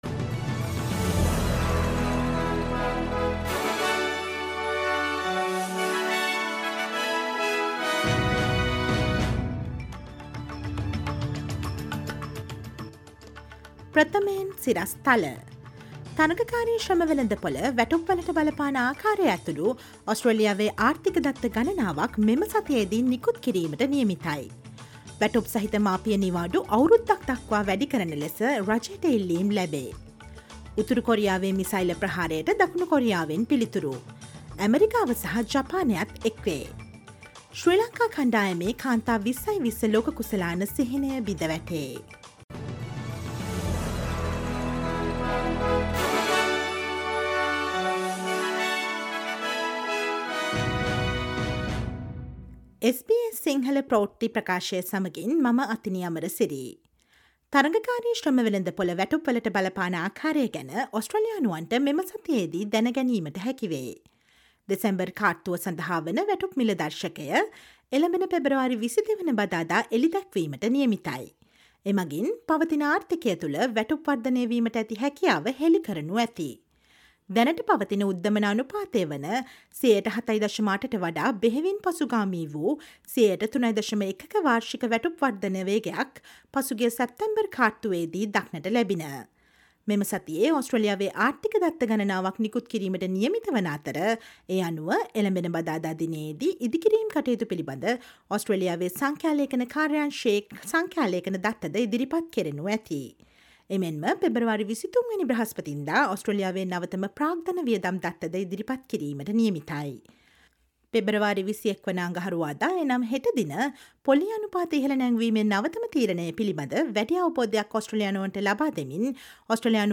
ඔස්ට්‍රේලියාවේ නවතම පුවත් , විදෙස් පුවත් සහ ක්‍රීඩා පුවත් රැගත් SBS සිංහල සේවයේ 2023 පෙබරවාරි 20 සඳුදා ප්‍රවෘත්ති ප්‍රකාශයට සවන් දෙන්න.